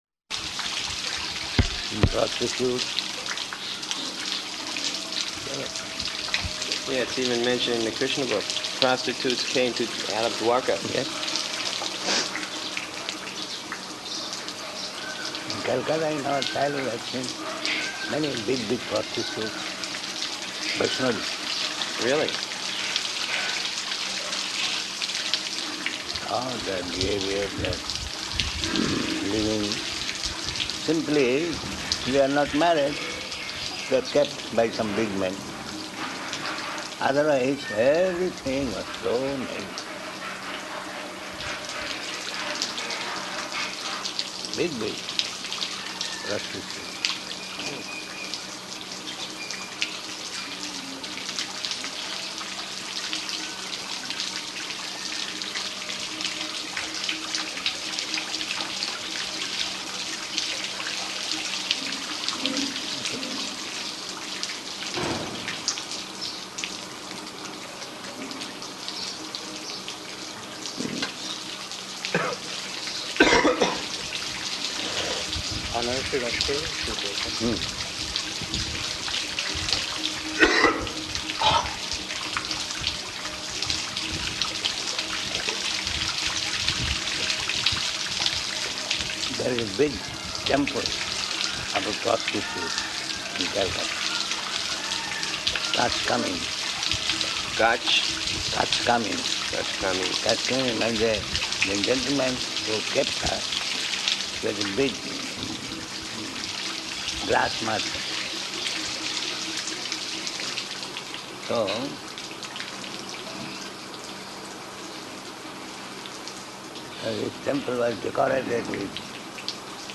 Morning Conversation